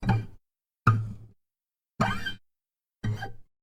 Fret Noise Plucks
Fret Noise Plucks is a free sfx sound effect available for download in MP3 format.
yt_Q1gQUqp1R-0_fret_noise_plucks.mp3